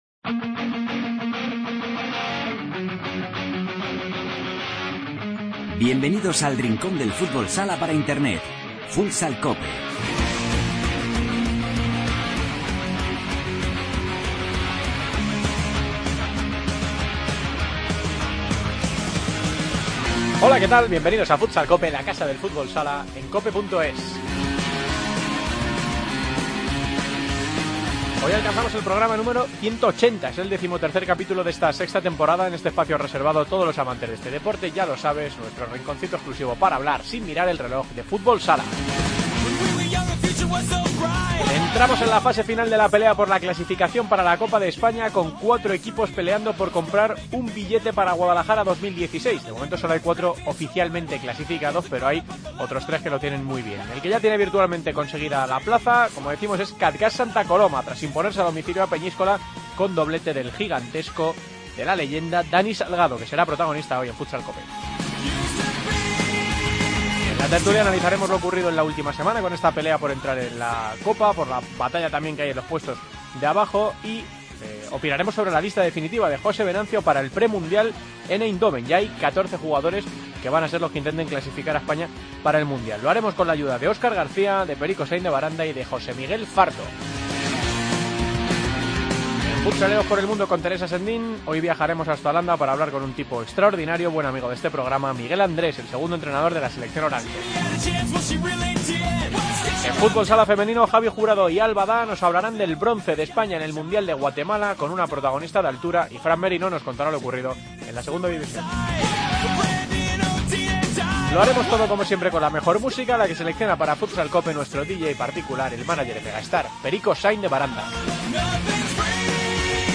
Segunda División y Michael Jackson como banda sonora.